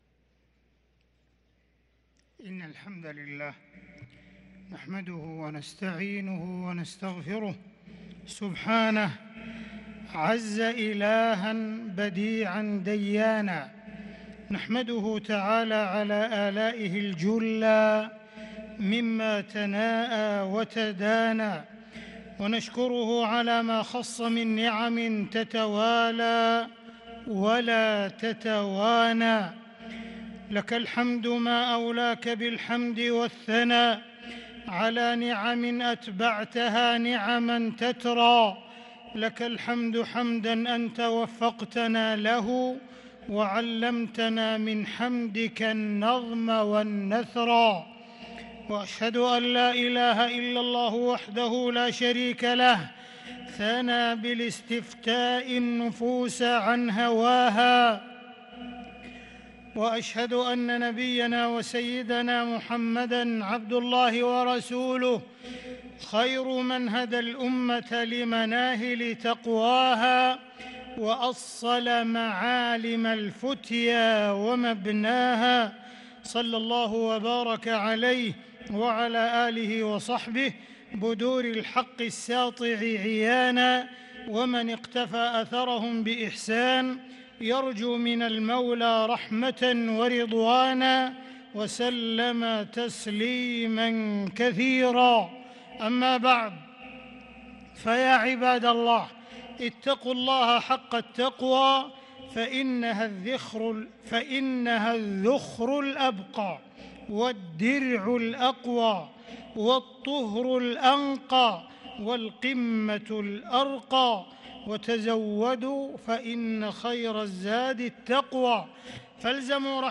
خطبة الجمعة 4 ذو القعدة 1443هـ | khutbat aljumuea 3-6-2022 > خطب الحرم المكي عام 1443 🕋 > خطب الحرم المكي 🕋 > المزيد - تلاوات الحرمين